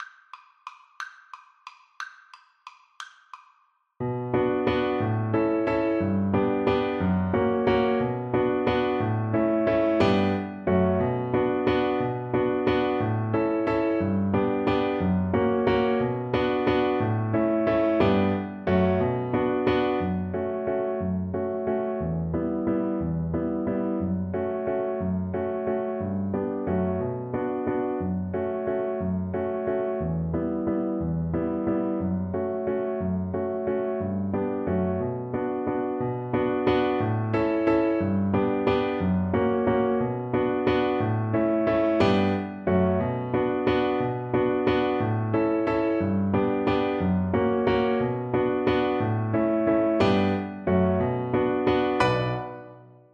Violin
3/4 (View more 3/4 Music)
One in a bar . = c. 60
B minor (Sounding Pitch) (View more B minor Music for Violin )
Traditional (View more Traditional Violin Music)
world (View more world Violin Music)